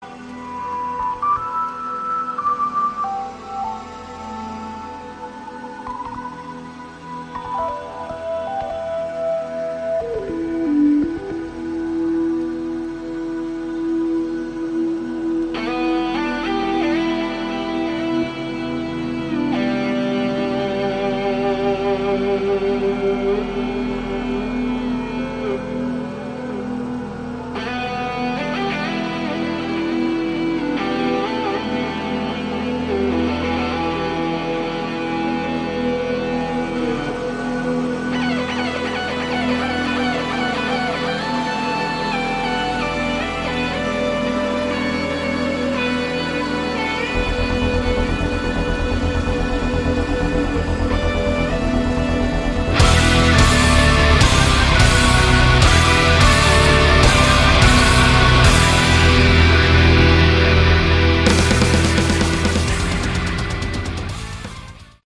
Category: Hard Rock/Melodic Metal
vocals
bass
guitars
keyboards
drums